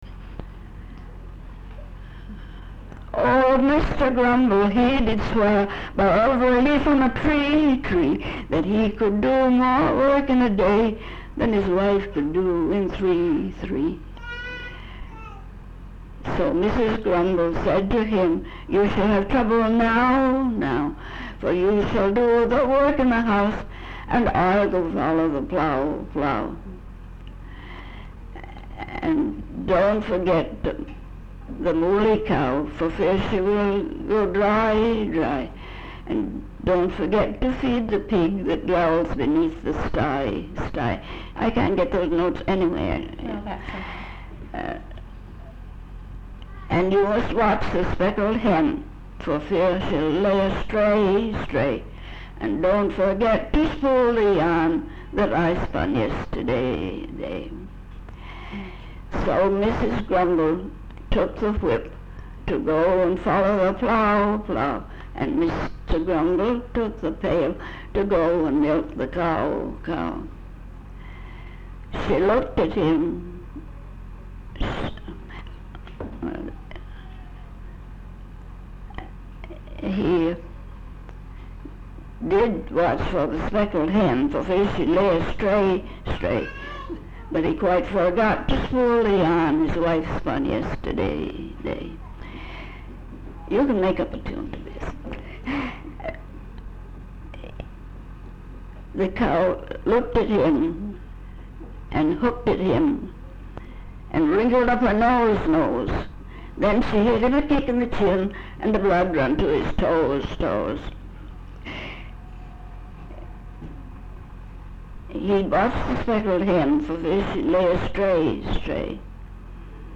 Folk songs, English--Vermont
sound tape reel (analog)
Location Jacksonville, Vermont